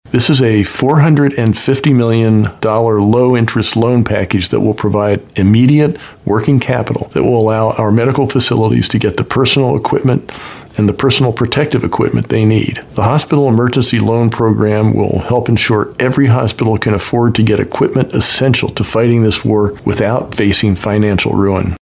Wolf explains what it’s all about: